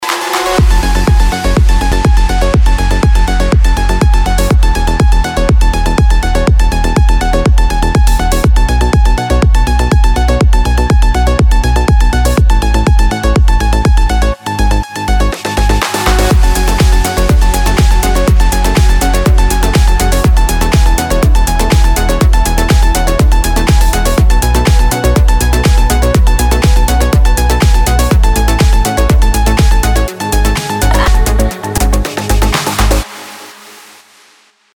Танцевальные рингтоны
Рингтоны без слов , Рингтоны техно
электронная музыка
Melodic house